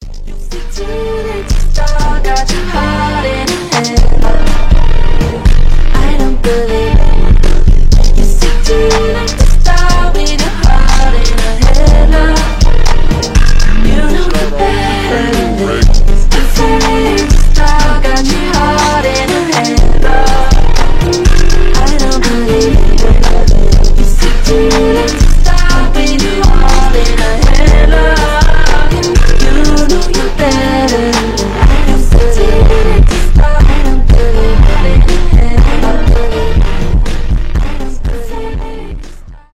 рэп , ремиксы
поп , мощные басы